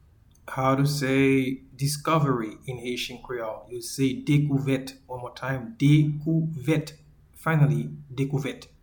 Pronunciation:
Discovery-in-Haitian-Creole-Dekouvet.mp3